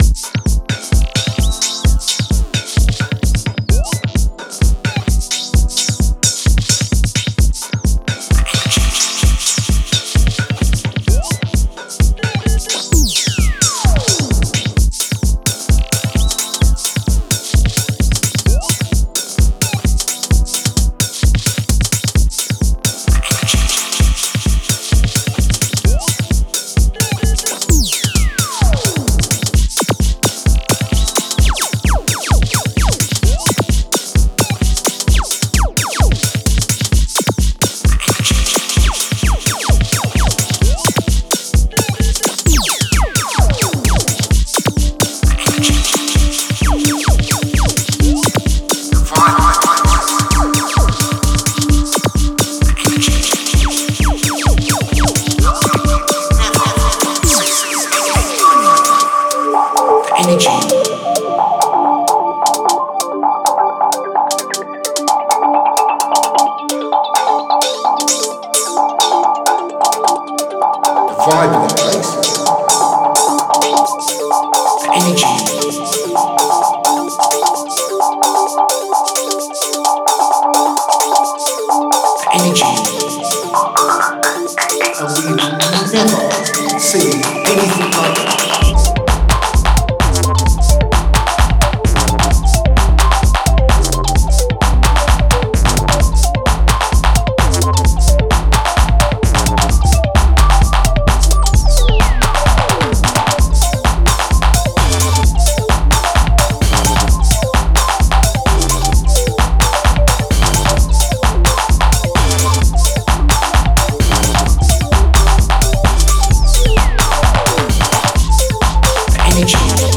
Genre: Electronic, Synthwave.